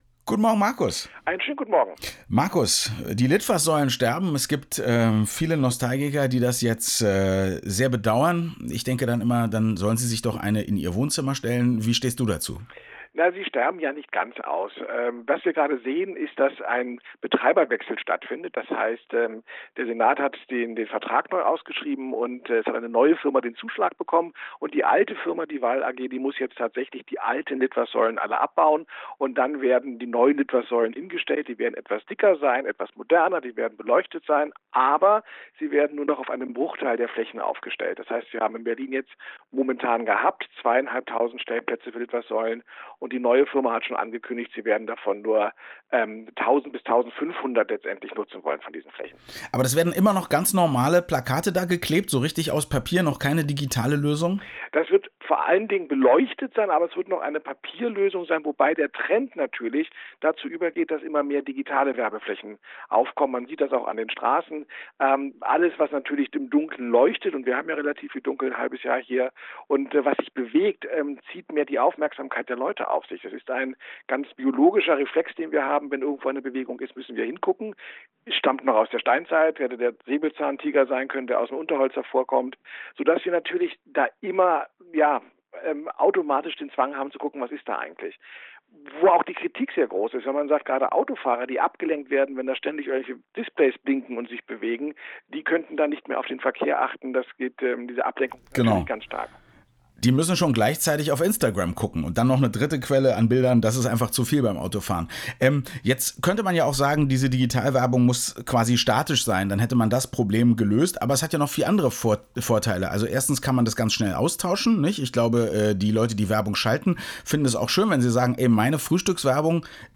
Diese Frage stellten mir die Herren von „Zweiaufeins“ diesmal am Telefon, da ich es aus Zeitgründen nicht ins radioeins-Studio schaffte: